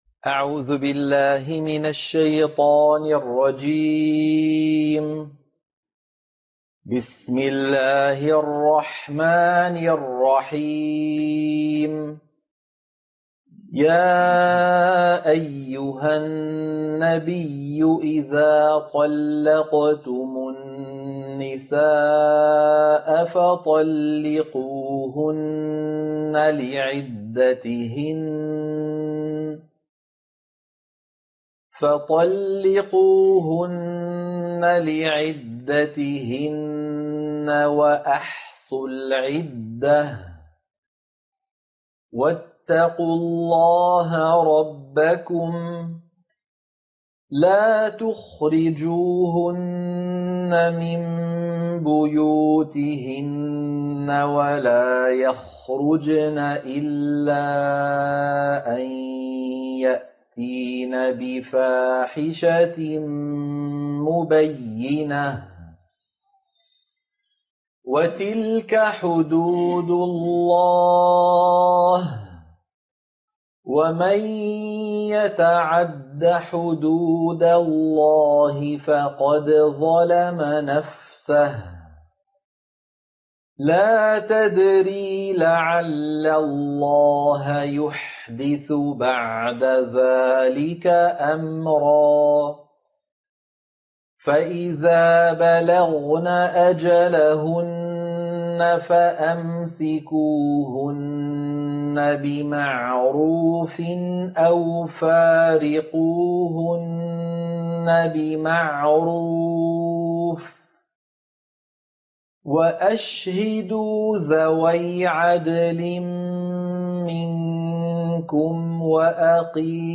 سورة الطلاق - القراءة المنهجية - الشيخ أيمن سويد